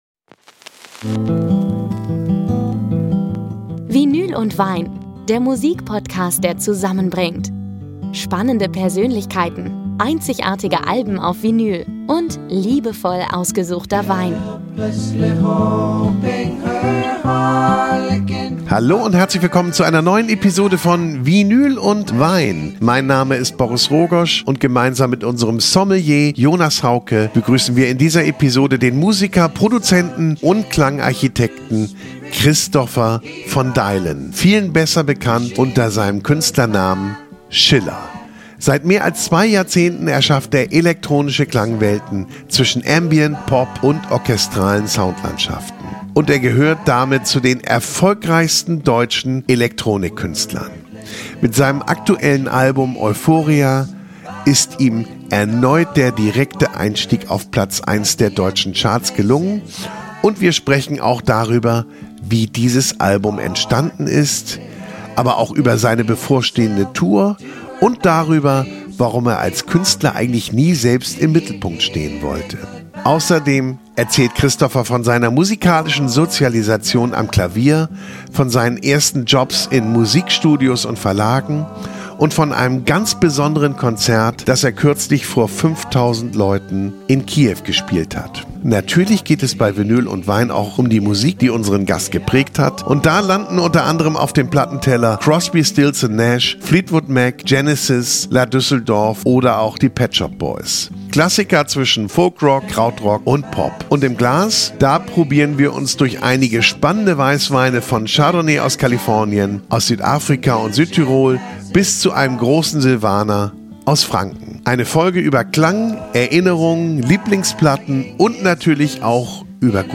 Beschreibung vor 1 Monat In dieser Episode von Vinyl & Wein ist Christopher von Deylen zu Gast – Komponist, Produzent sowie Schöpfer und kreativer Kopf hinter dem Musikprojekt Schiller und einer der erfolgreichsten deutschen Elektronik-Künstler.